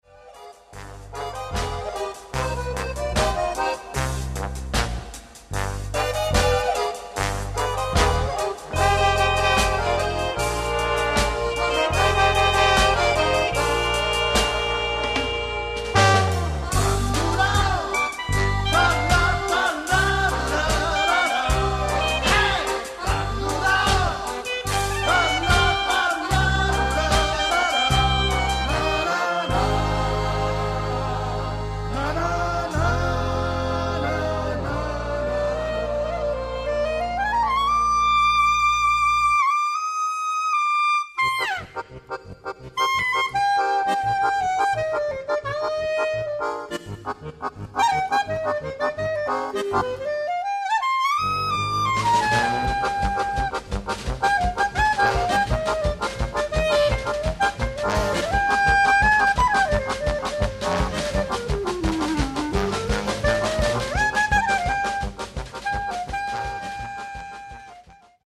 Das Blech ist auf dem Vormarsch.